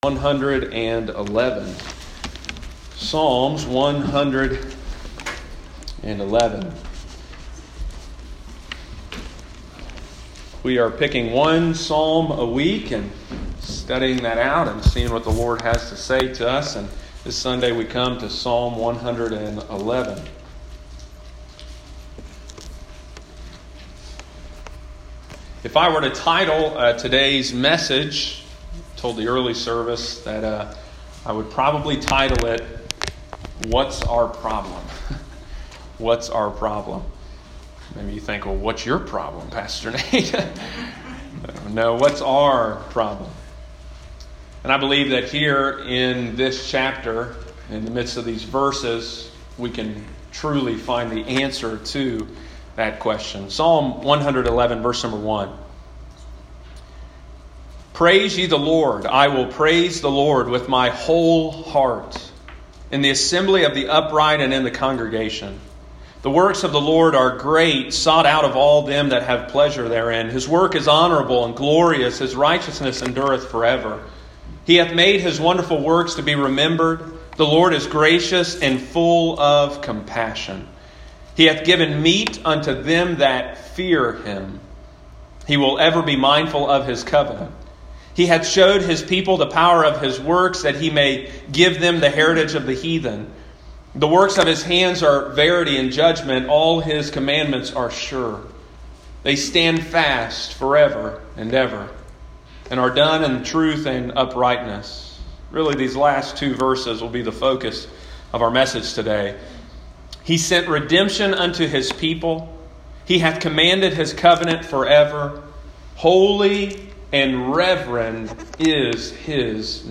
Sunday morning, August 23, 2020.